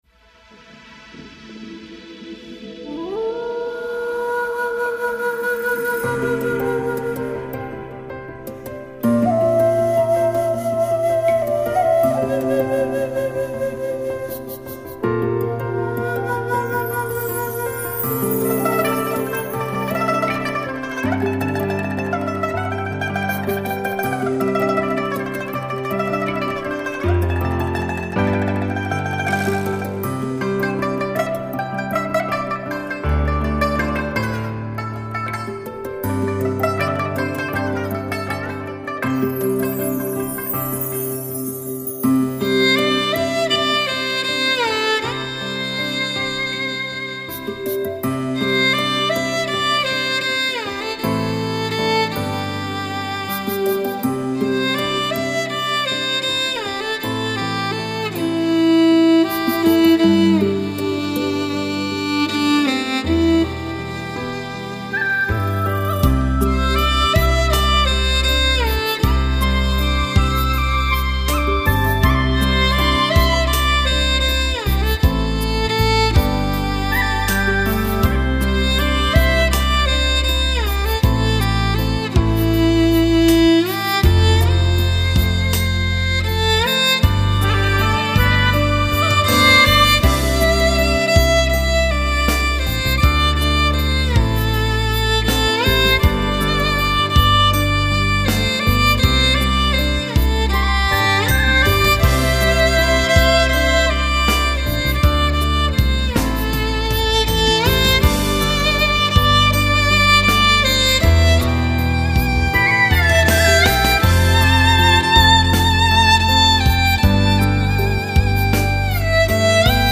马头琴
国际化的制作，历史性的突破，马头琴联袂新疆各民族乐器，与热瓦普、弹拨尔、艾捷克和谐融合。